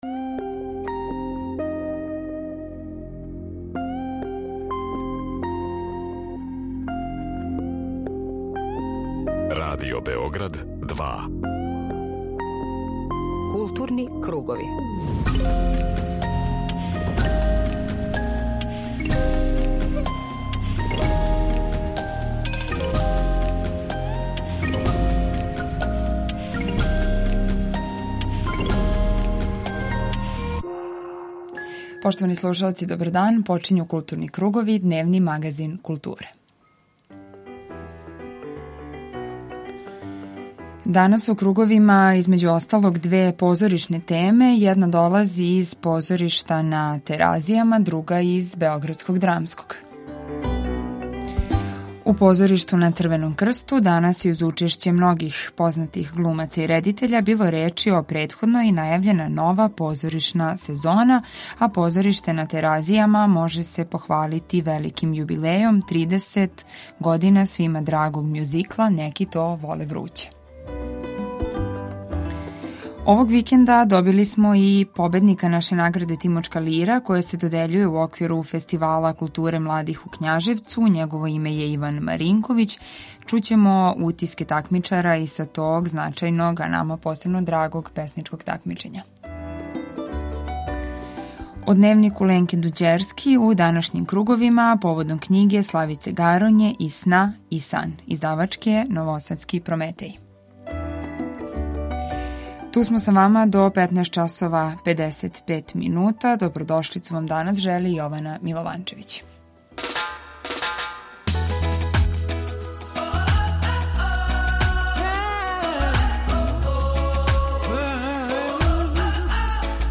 Овог викенда у Књажевцу у оквиру Фестивала културе младих додељена је и песничка награда „Тимочка лира" најбољем песнику до 30 година. Доносимо извештај и разговарамо са победником.